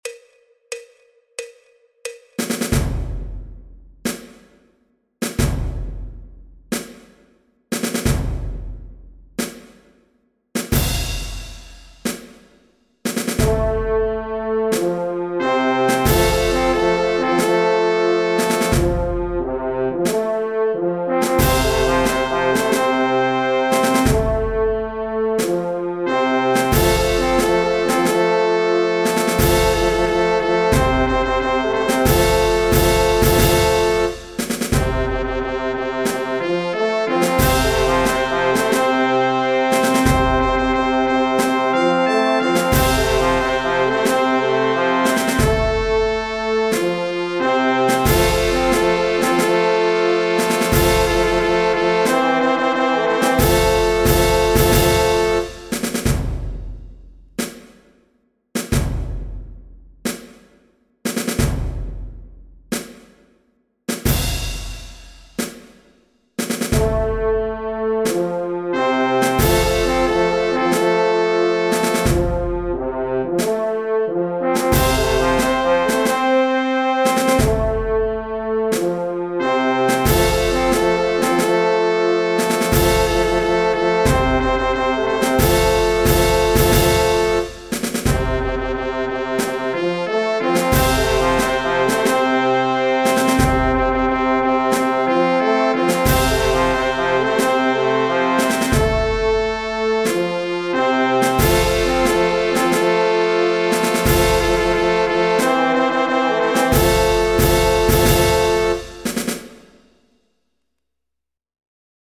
Marchas de Procesión
en Re Mayor (D)
El MIDI tiene la base instrumental de acompañamiento.
Música clásica